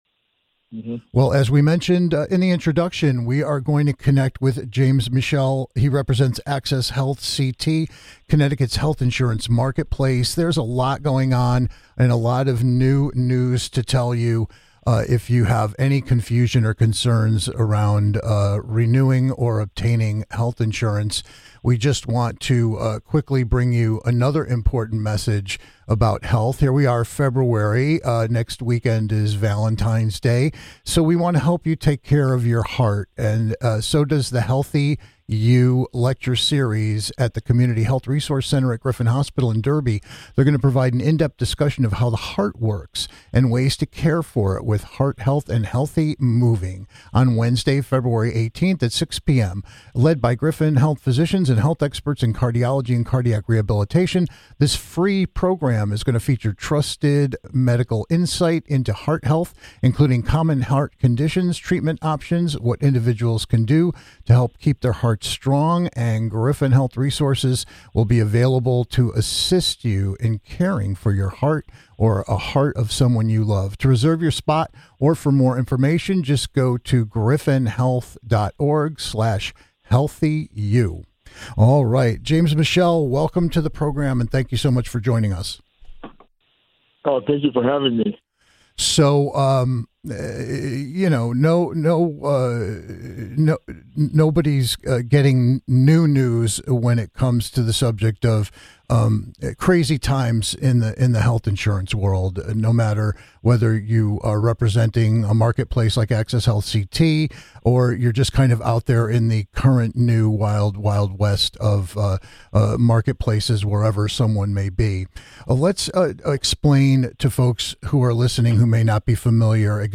Hear from a representative from Access Health CT the Connecticut health insurance marketplace.